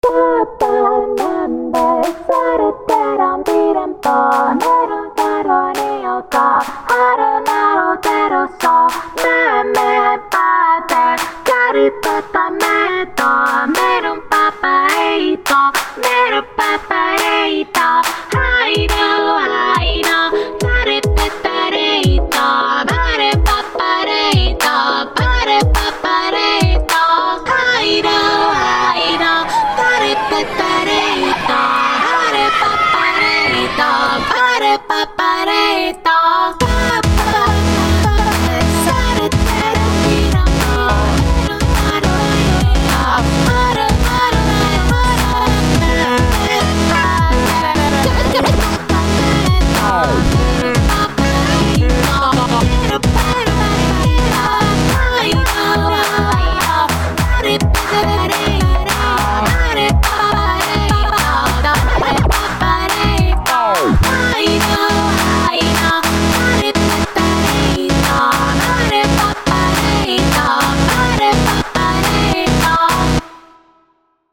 I decided to sing a song in gibberish! haha but I hope you guys like it!